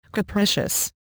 capricious1.mp3